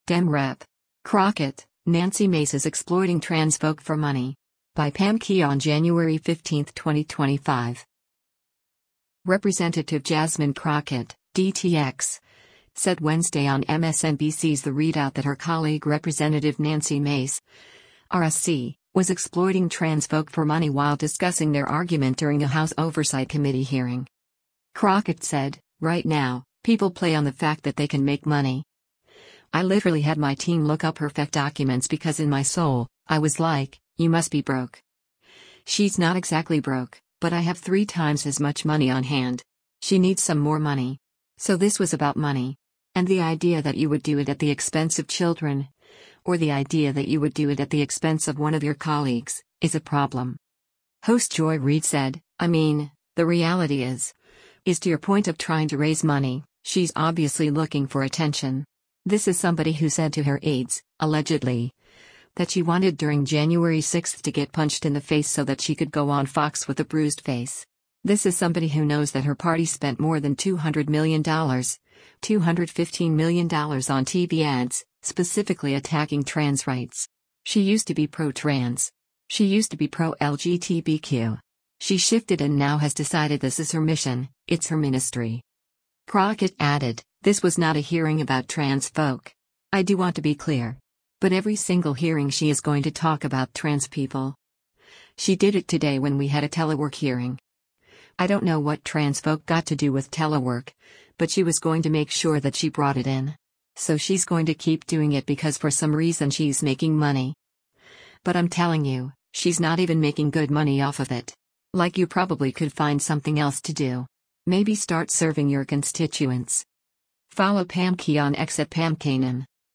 Representative Jasmine Crockett (D-TX) said Wednesday on MSNBC’s “The ReidOut” that her colleague Rep. Nancy Mace (R-SC) was exploiting “trans folk” for money while discussing their argument during a House Oversight Committee hearing.